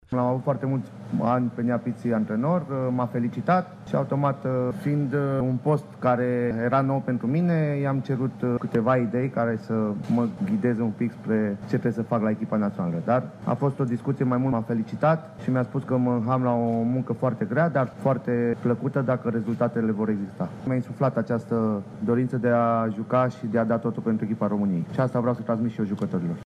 Selecţionerul echipei naţionale a României, Cosmin Contra, a declarat, într-o conferinţă de presă, că speră ca în meciurile cu Kazahstan şi Danemarca, ultimele două din campania de calificare la Cupa Mondială din 2018, să se vadă o mică schimbare în jocul primei reprezentative.